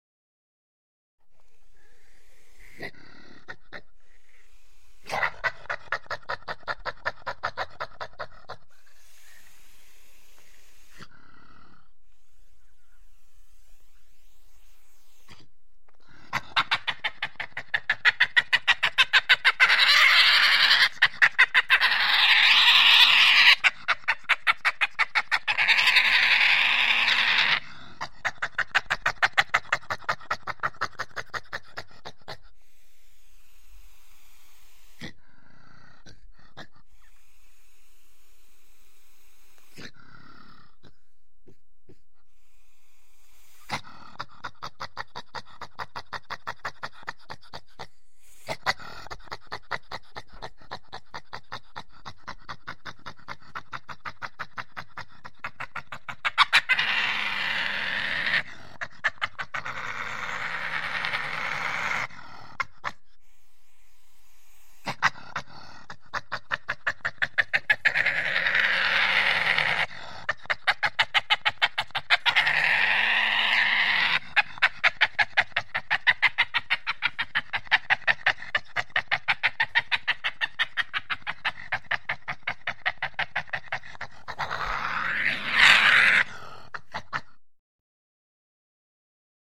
Звуки барсука
Рявканье